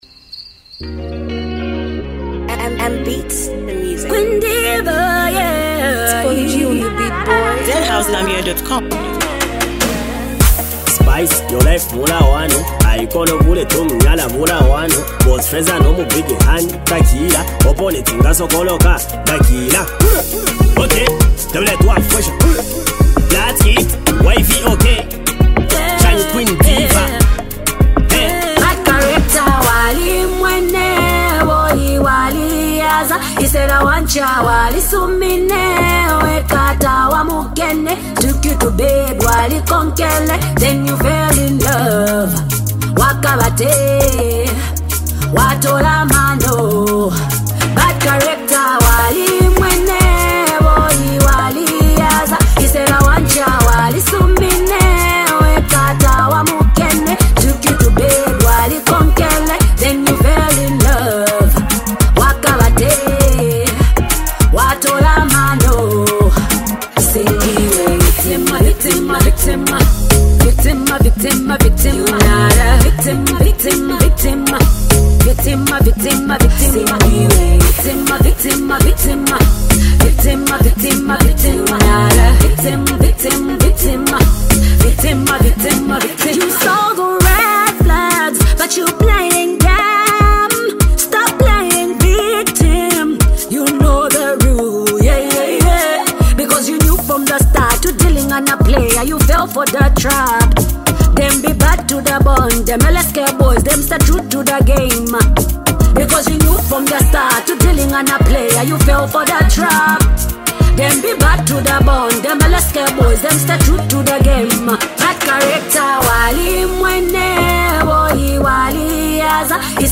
a fiery anthem packed with attitude and energy!